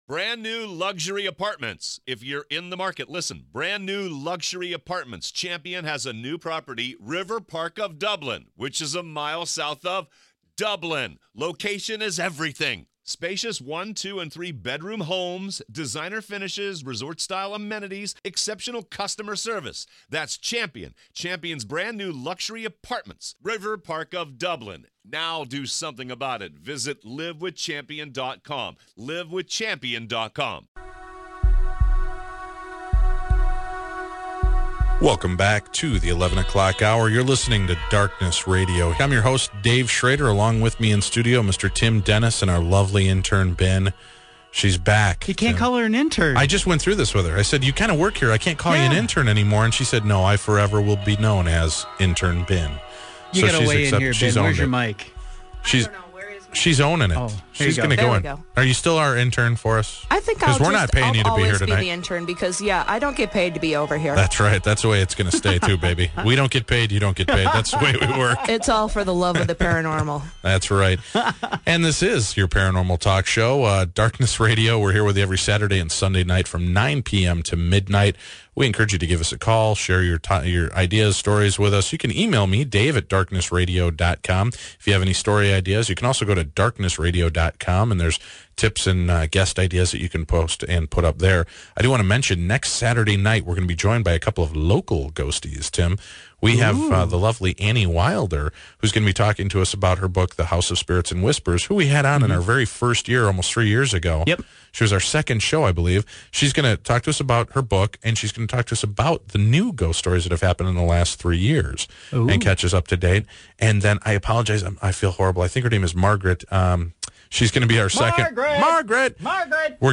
We also play some samples of those Electronic Voice Phenomena!